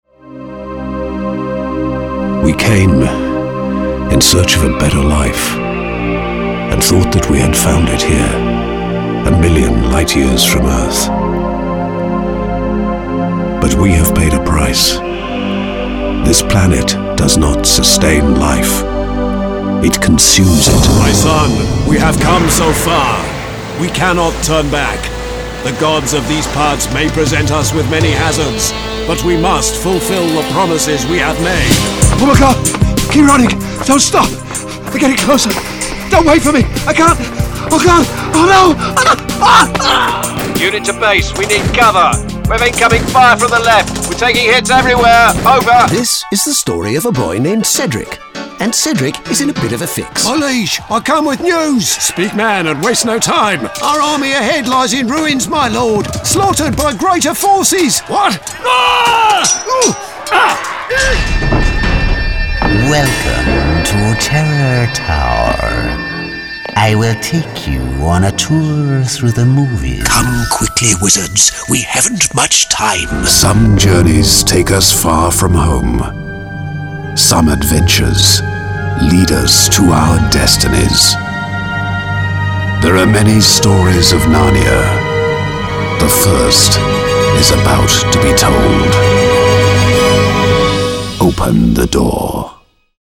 Englisch (Britisch)
Videospiele
Meine Stimme wird normalerweise als warm, natürlich und unverwechselbar beschrieben und wird oft verwendet, um Werbe- und Erzählprojekten Klasse und Raffinesse zu verleihen.
Neumann U87-Mikrofon